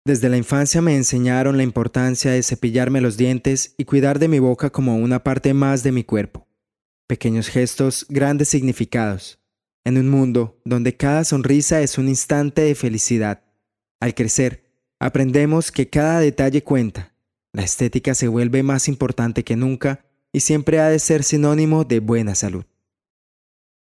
Colombian voice over. Colombian voice over agency
Colombian male voices